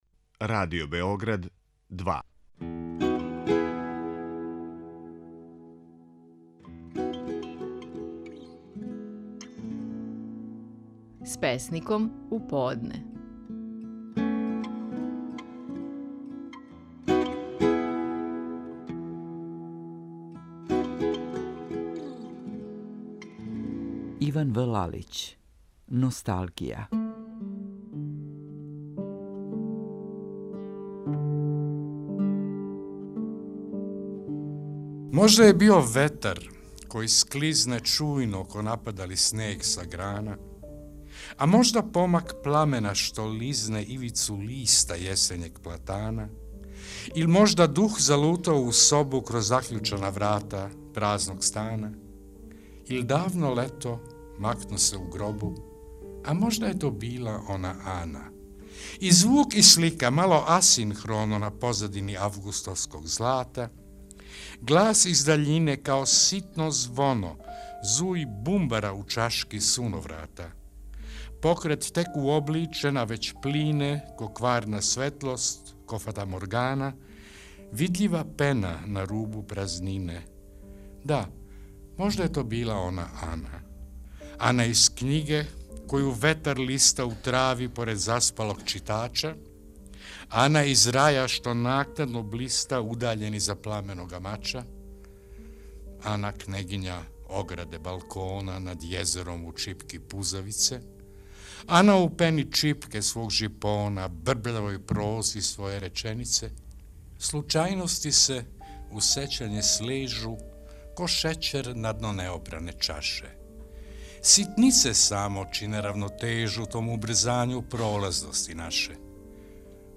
Стихови наших најпознатијих песника, у интерпретацији аутора.
Иван В. Лалић говори песму „Носталгија".